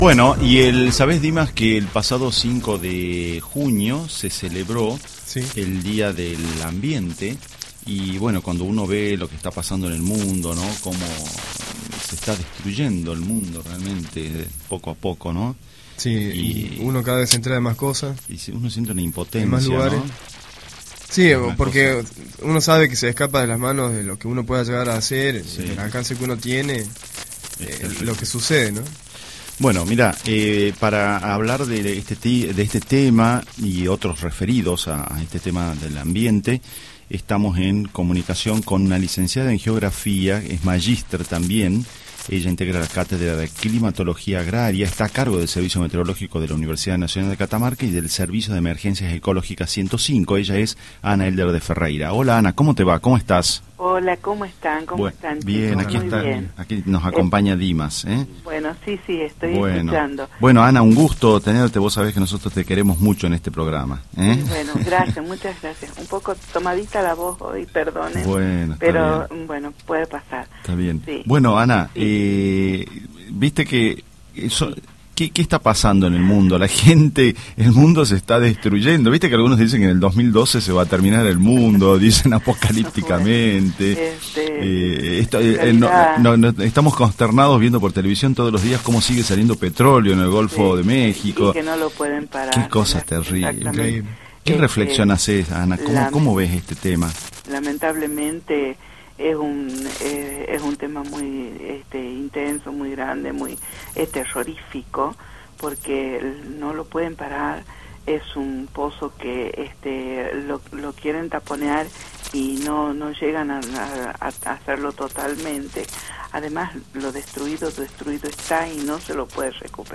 El 5 de junio se celebra el Día del Medio Ambiente. Para recordar la fecha le hicimos un reportaje